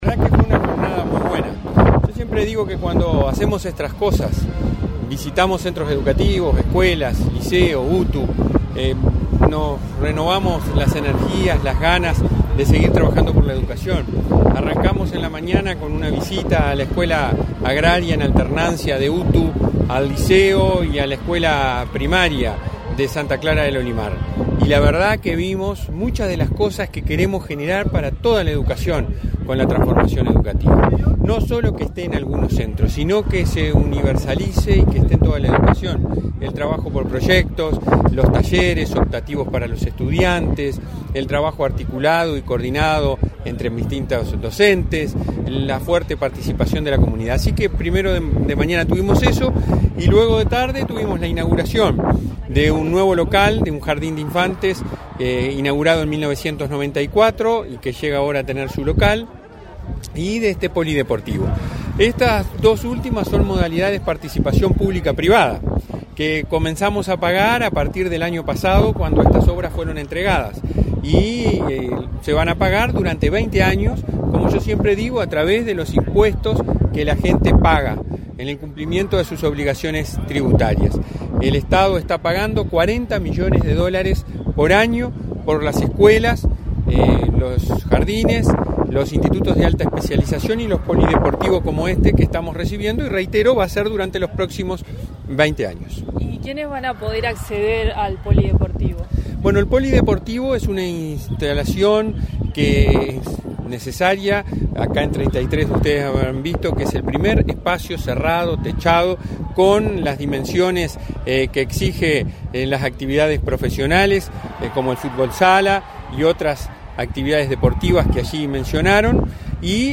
Entrevista al presidente de la ANEP, Robert Silva
Entrevista al presidente de la ANEP, Robert Silva 03/11/2022 Compartir Facebook X Copiar enlace WhatsApp LinkedIn Tras participar en actividades en el departamento de Treinta y Tres, este 3 de noviembre, el presidente de la Administración Nacional de Educación Pública (ANEP) realizó declaraciones a Comunicación Presidencial.